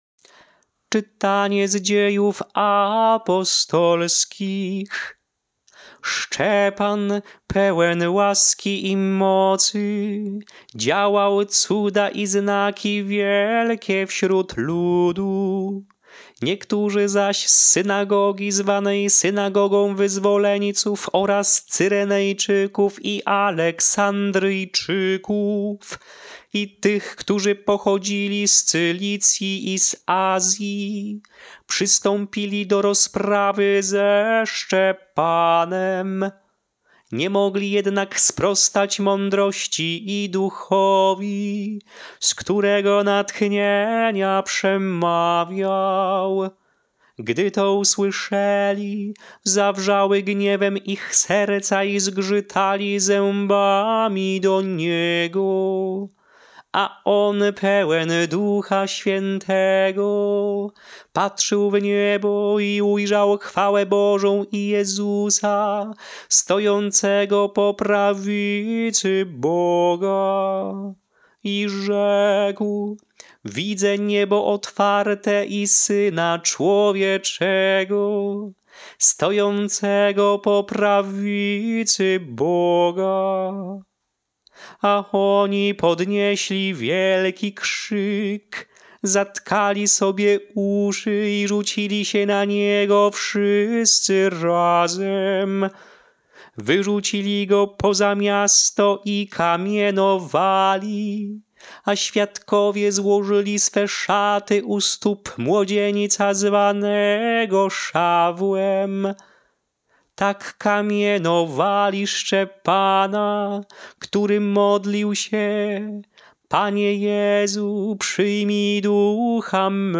Śpiewane lekcje mszalne – Święto św.
Melodie lekcji mszalnej przed Ewangelią w Święto św. Szczepana Męczennika:
Sw.-Szczepana-ton-epistoly-.mp3